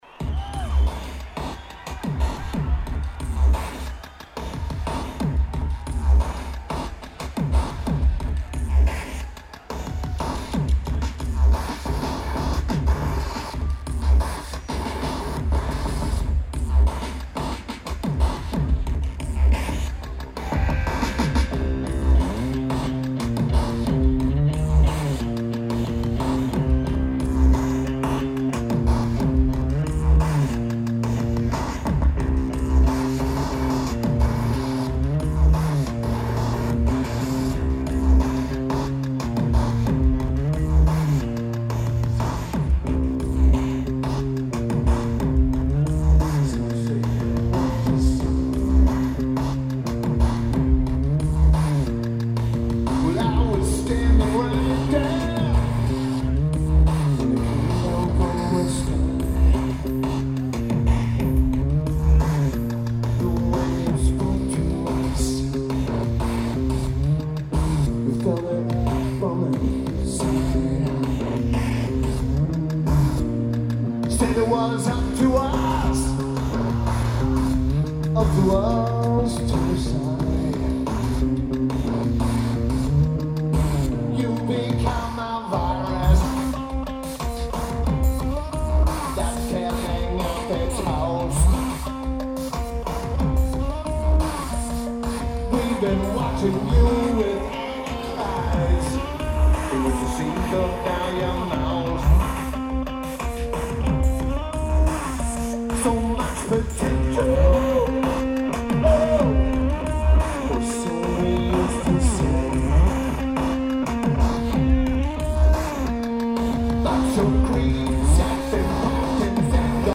DTE Energy Music Theatre